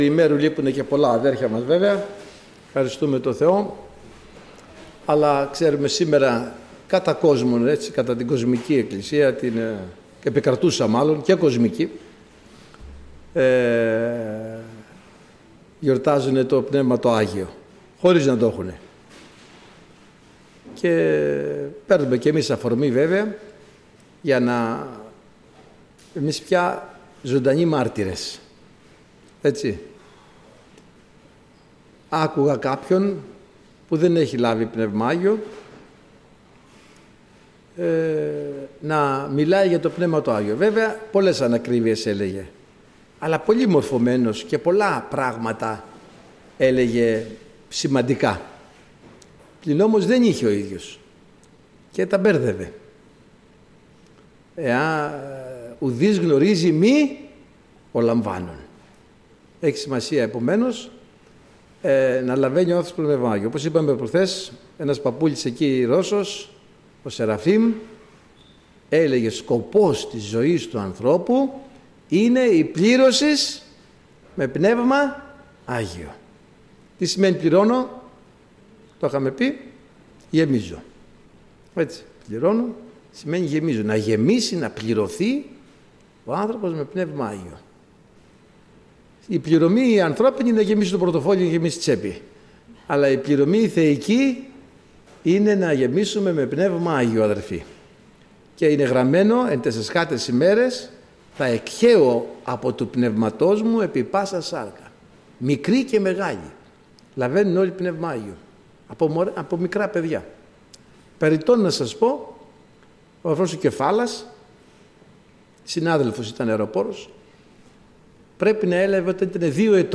Μηνύματα Αδερφών για την ημέρα του Αγίου Πνεύματος Ομιλητής: Διάφοροι Ομιλητές Λεπτομέρειες Σειρά: Κηρύγματα Ημερομηνία: Δευτέρα, 09 Ιουνίου 2025 Εμφανίσεις: 138 Γραφή: Ιωήλ 2:28-32 Λήψη ήχου